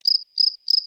Cricket - Сверчок
Отличного качества, без посторонних шумов.
198_cricket.mp3